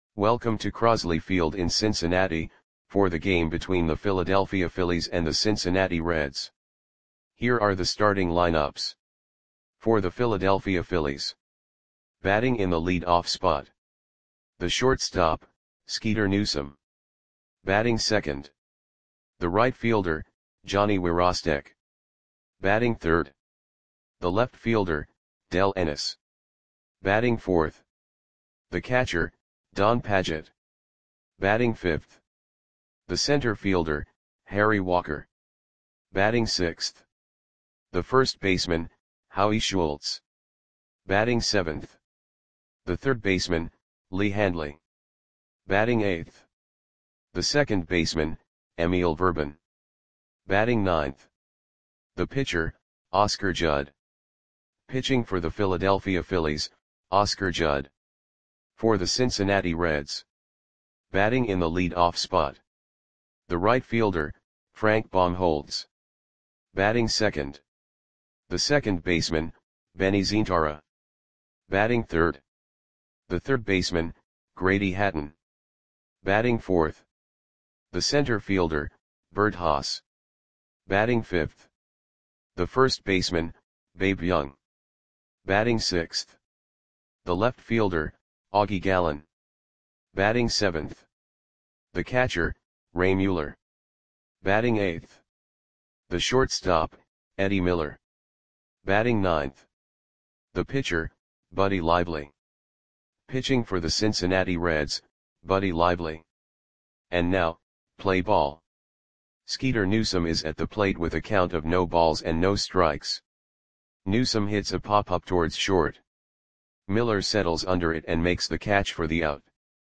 Audio Play-by-Play for Cincinnati Reds on July 27, 1947